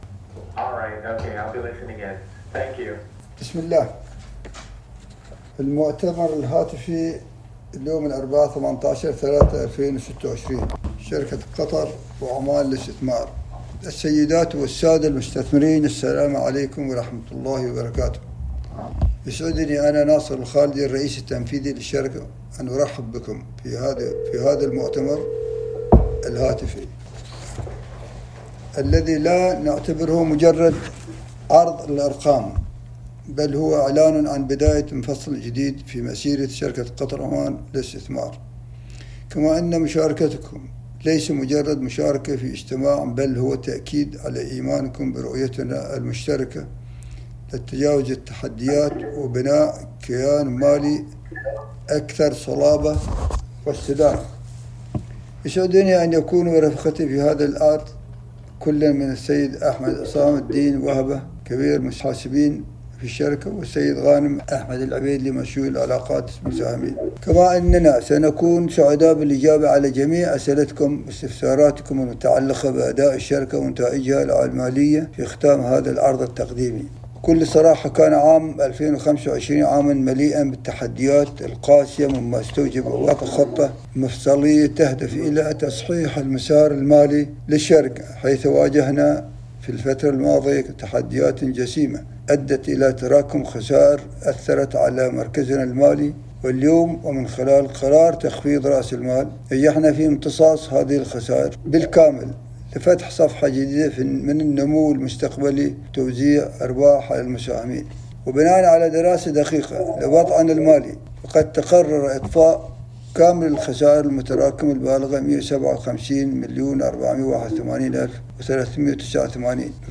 المكالمة الجماعية